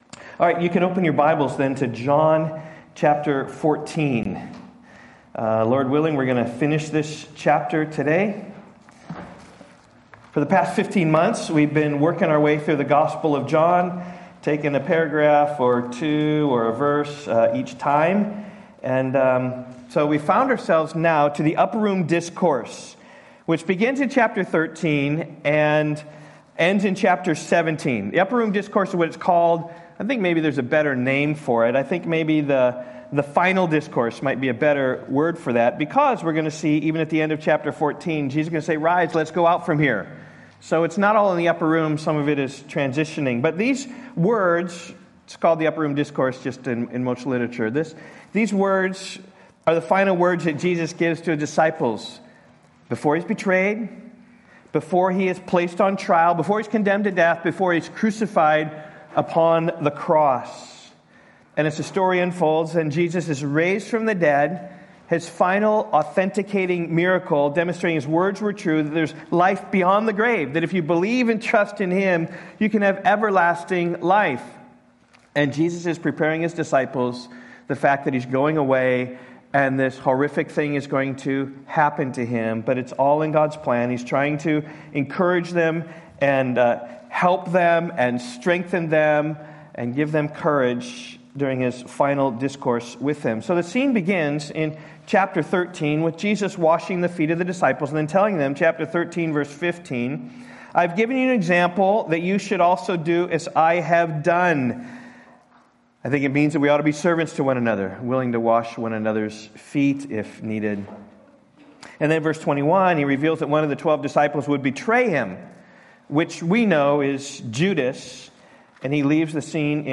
Sermon audio from Rock Valley Bible Church.